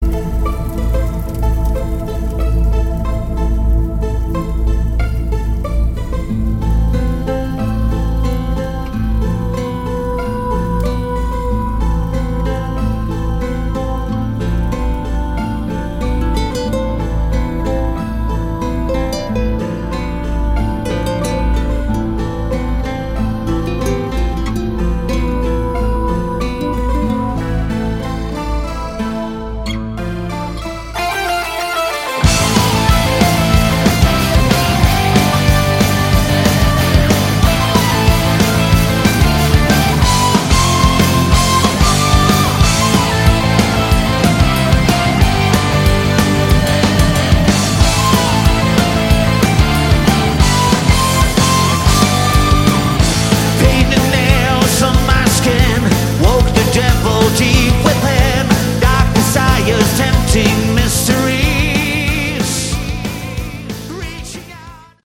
Category: Melodic Metal
vocals
guitar
bass
drums
keyboards